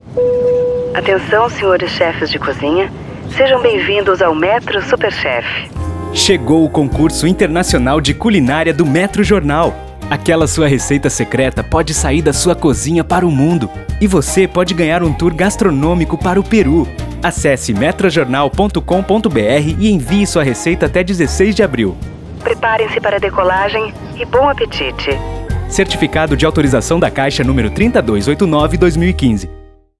Jornal Metro Campanha Metro Superchef 2015. Produção de conteúdos e criação de spots para rádio.